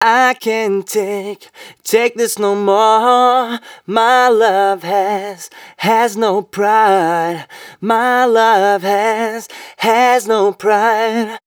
036 male.wav